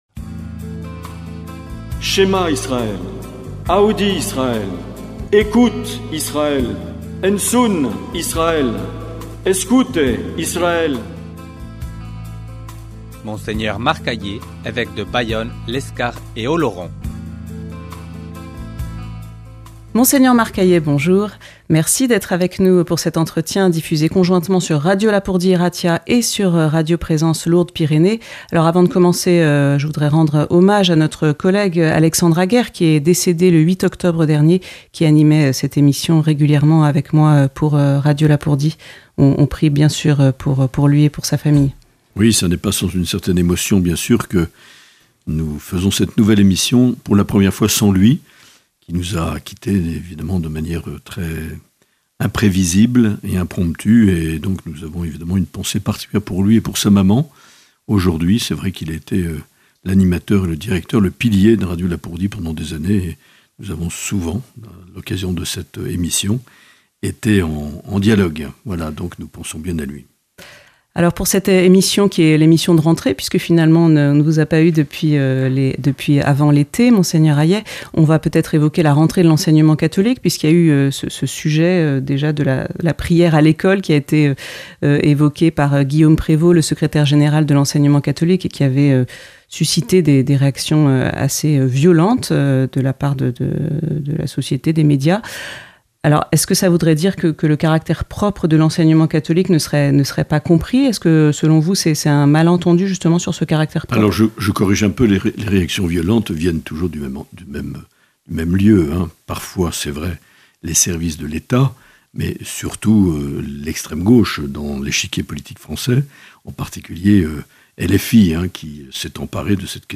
L’entretien avec Mgr Marc Aillet - Octobre 2025